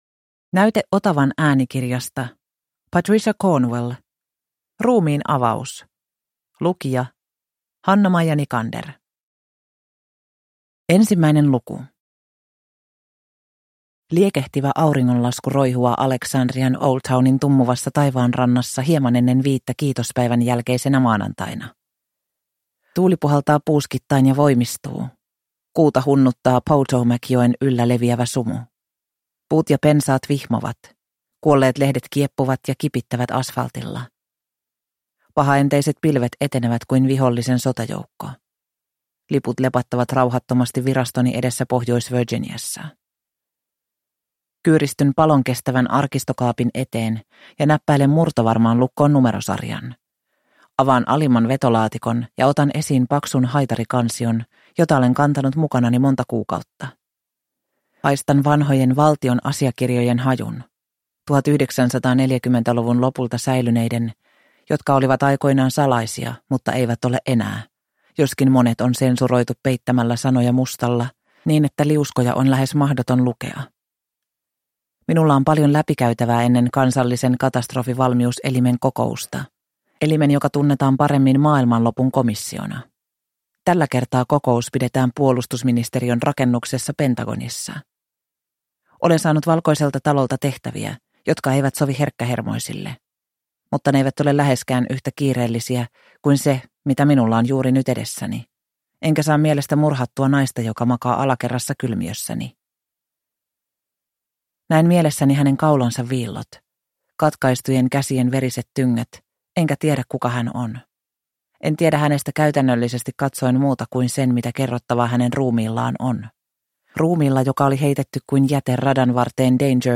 Ruumiinavaus – Ljudbok – Laddas ner